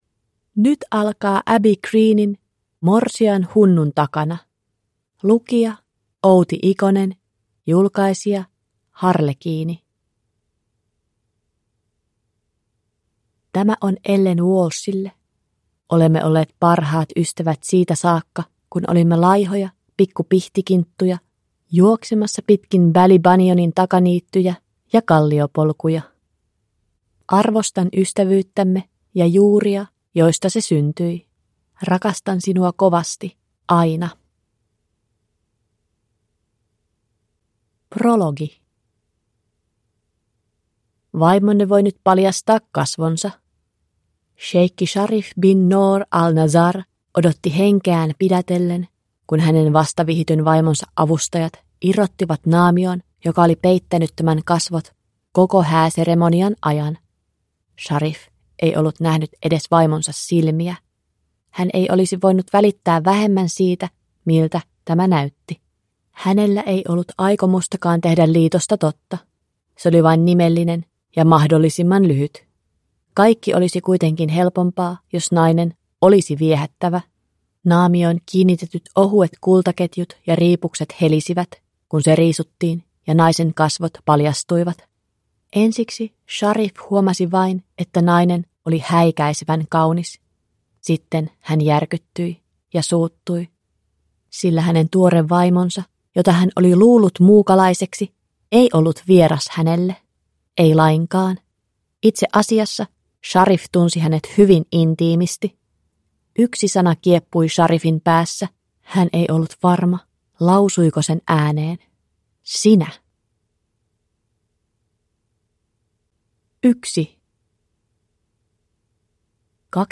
Morsian hunnun takana (ljudbok) av Abby Green